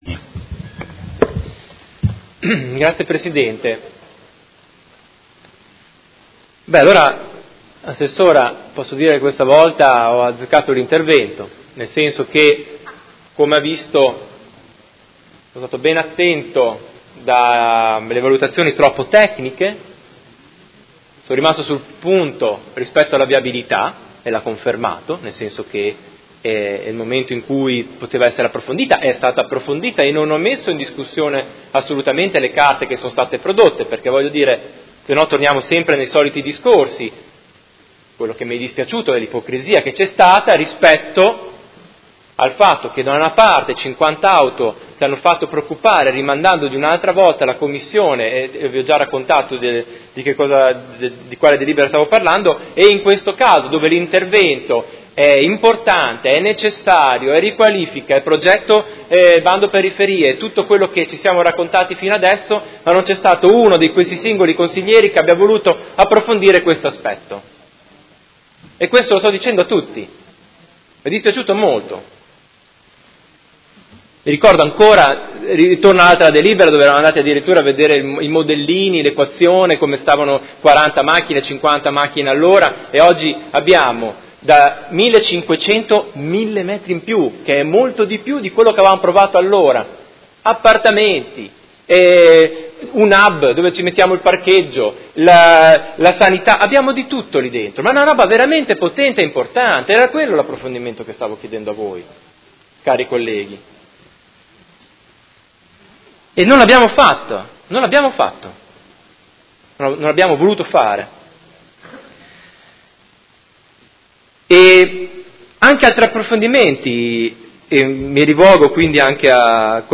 Seduta del 21/03/2019 Dichiarazione di voto.
Audio Consiglio Comunale